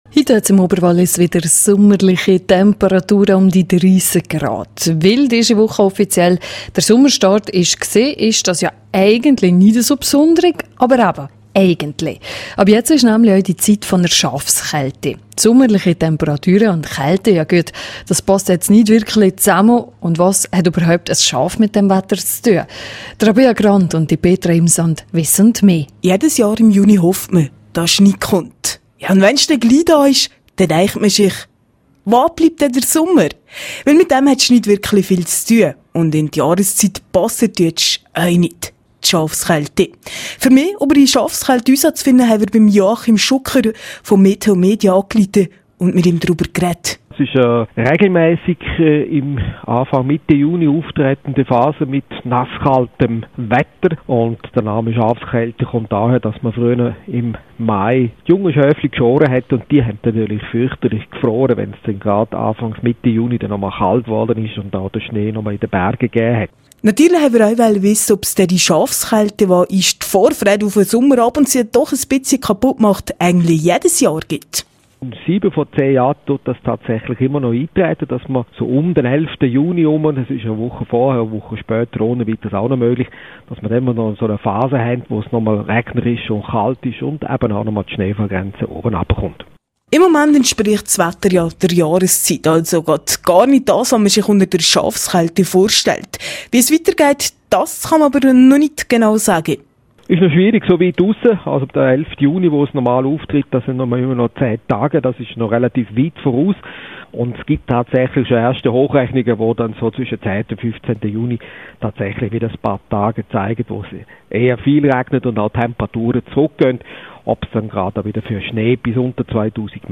Vorfreude auf den Cupfinal! Reto Ziegler im rro-Interview.